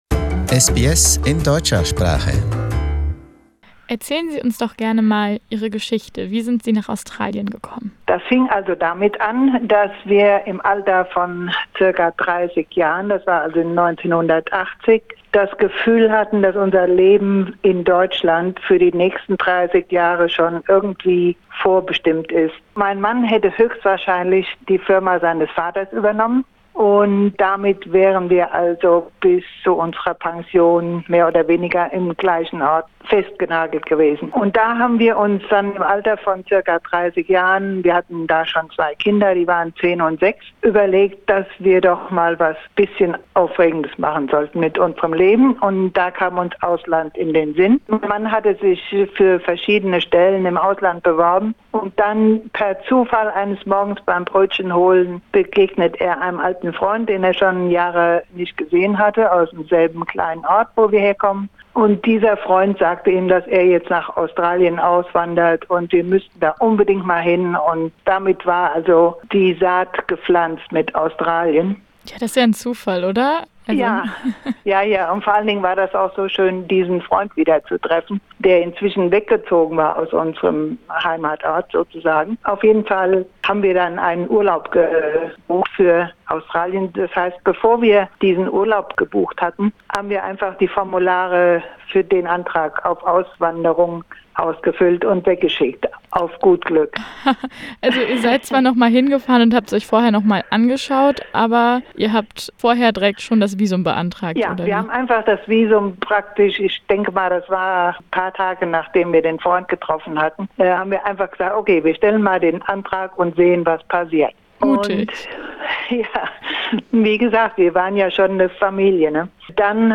German sheep farmer in Australia: An interview